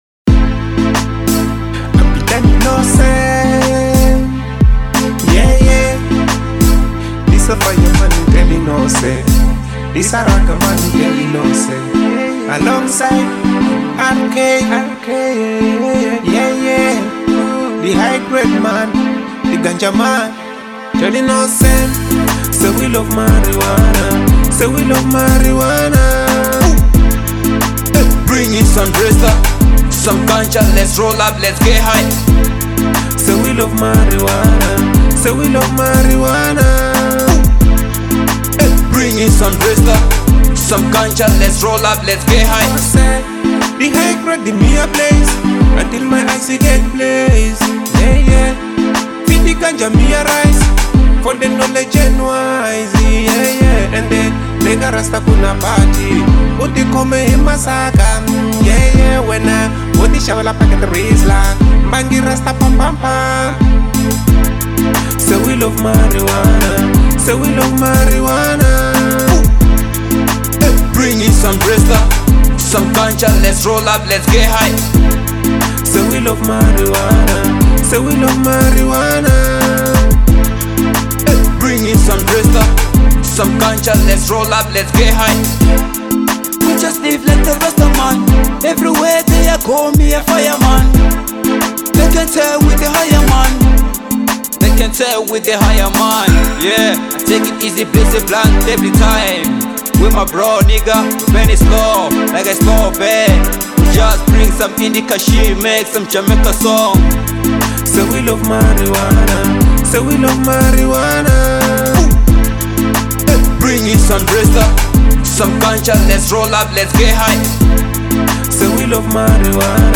Genre : Reggae